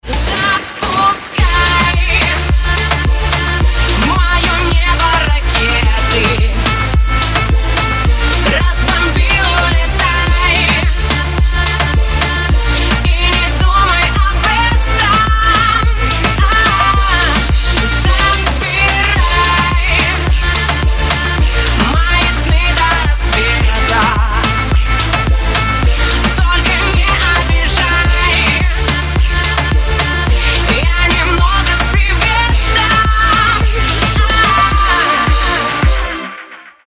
• Качество: 320, Stereo
dance
house